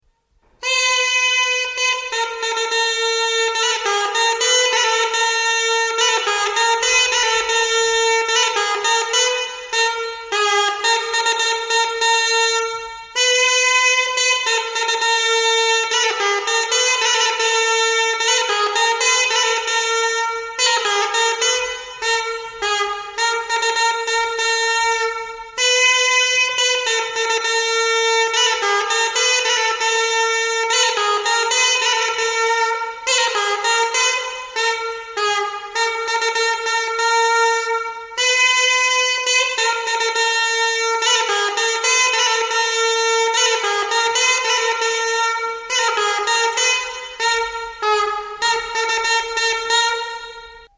The ozragis is a nimble instrument, but difficult to control.
Ozragis: Piemenų maršas